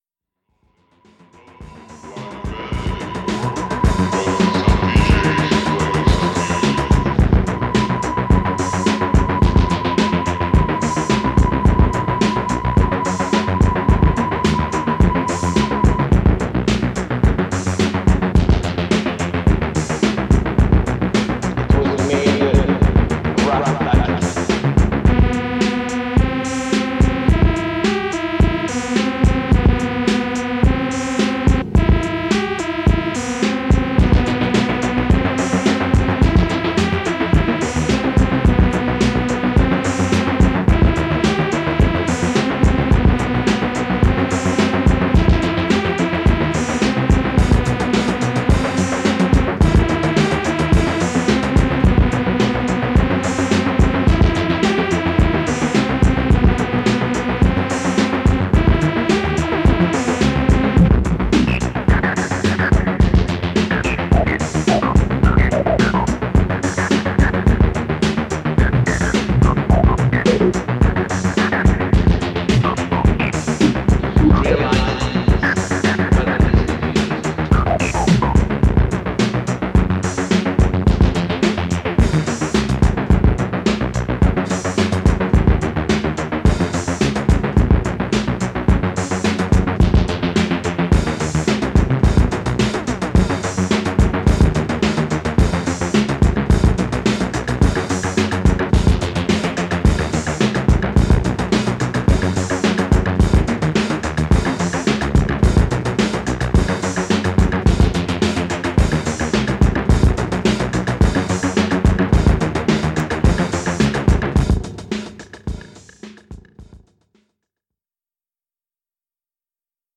Well crafted Electro/ EBM hybrids